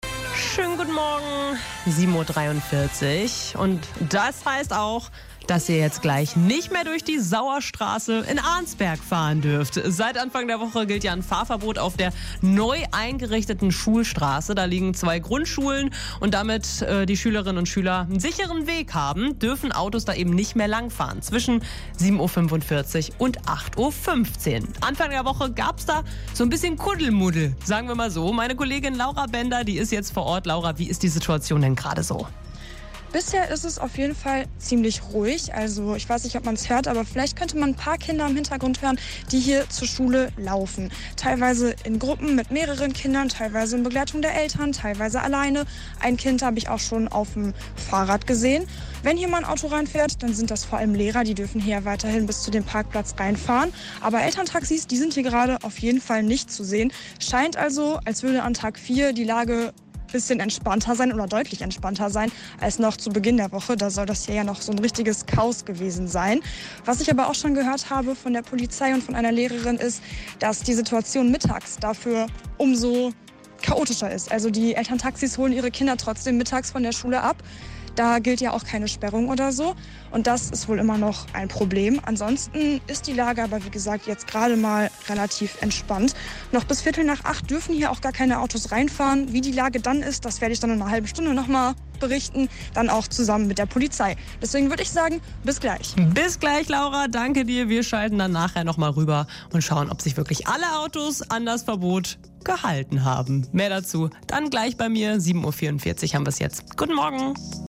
mitschnitt-liveschalte-schulstrasse.mp3